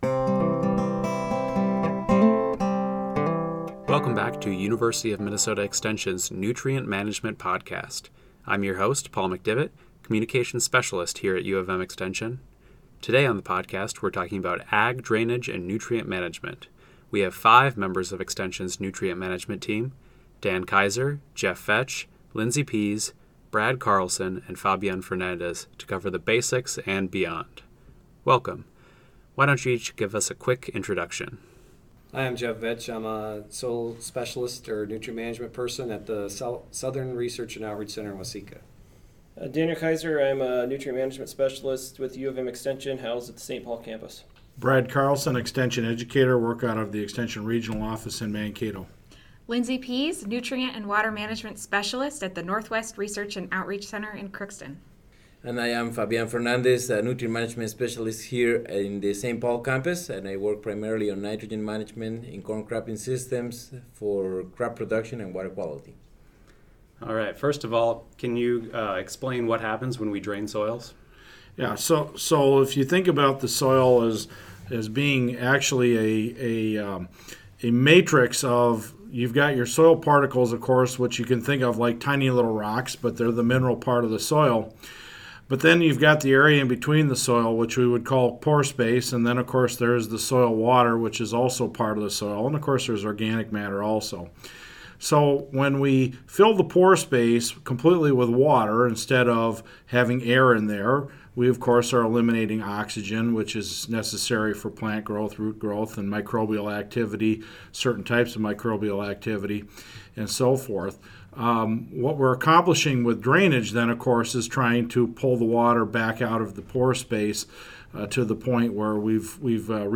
In this episode of the Nutrient Management Podcast, University of Minnesota Extension specialists and educators discuss agricultural drainage and nutrient management.